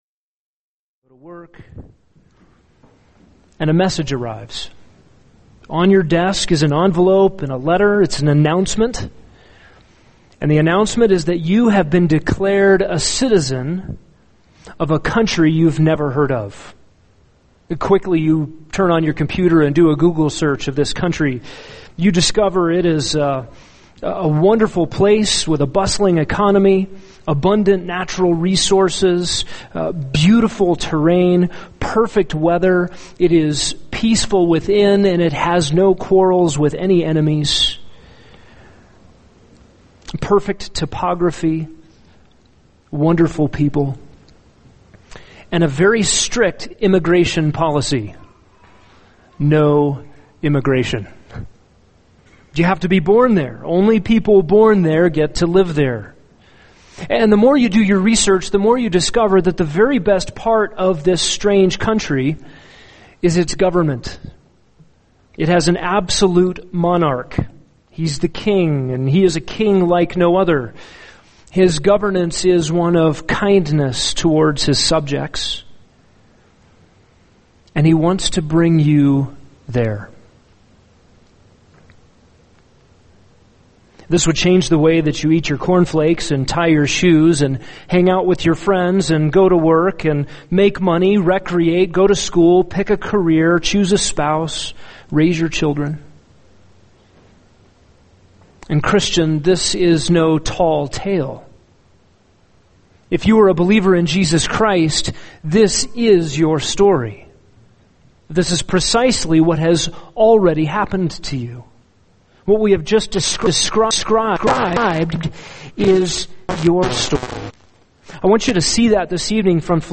[sermon] Philippians 3:20-21 – Homesick for Your Permanent Residence | Cornerstone Church - Jackson Hole